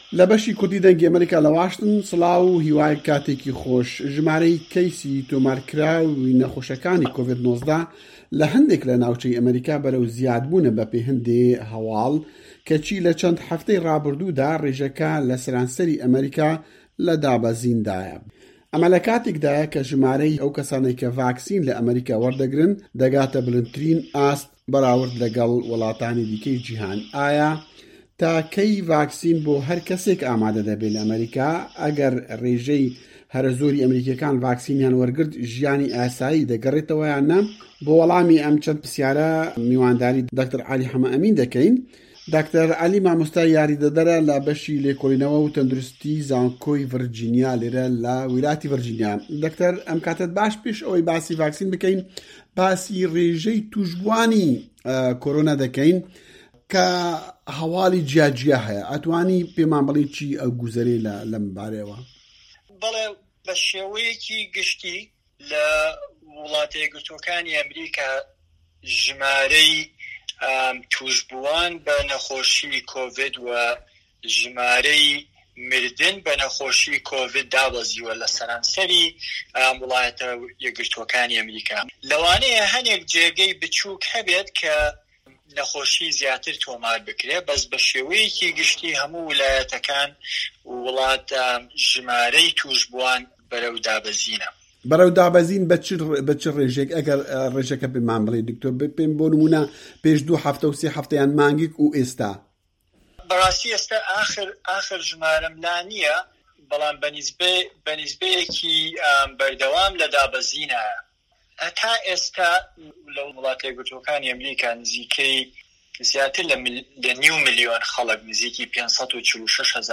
زانیاری زیاتر لە دەقی وتووێژەکەدایە: